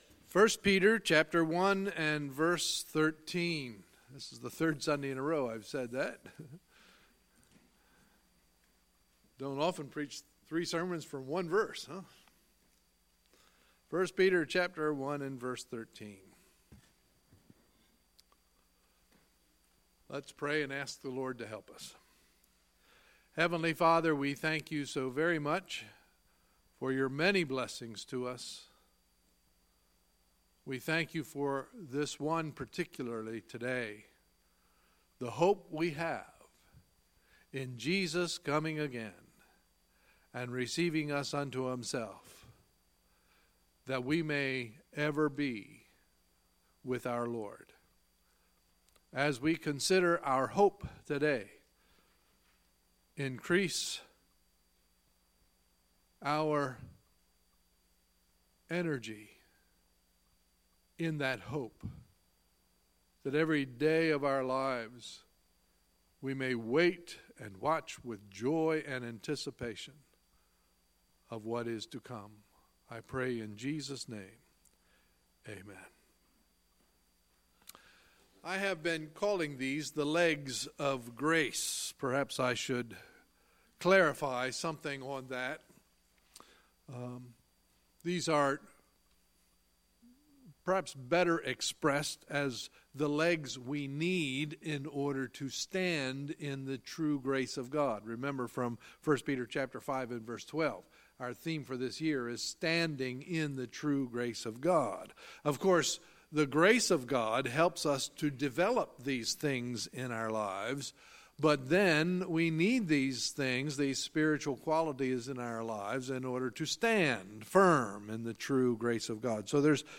Sunday, February 25, 2018 – Sunday Morning Service